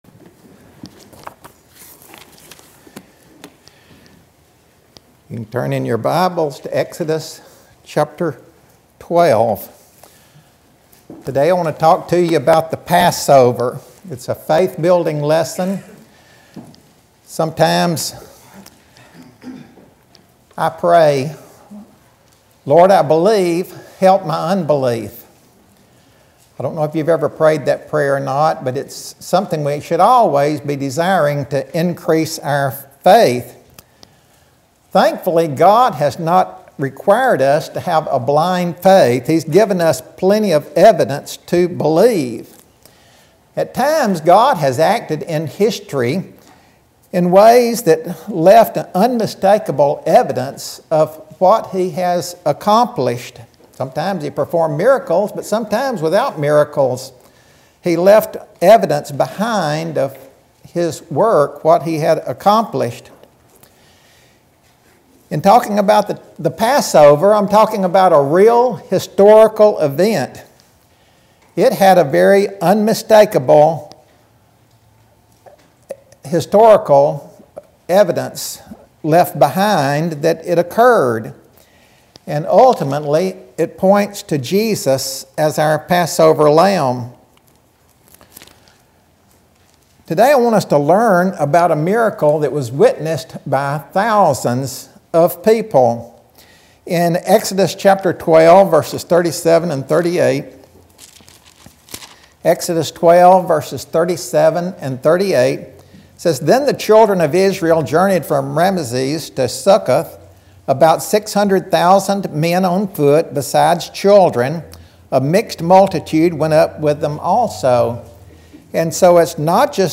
Sermon – Passover